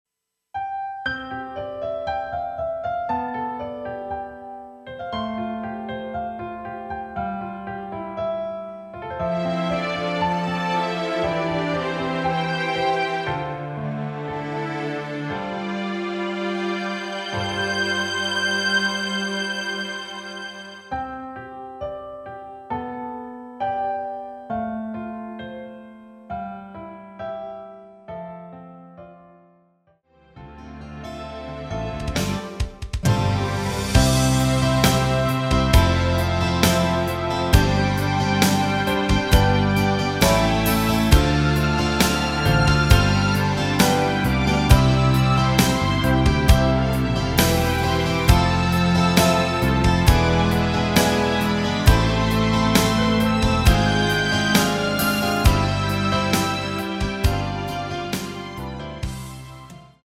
대부분의 남성분이 부르실수 있는 키로 제작 하였습니다.
앞부분30초, 뒷부분30초씩 편집해서 올려 드리고 있습니다.
중간에 음이 끈어지고 다시 나오는 이유는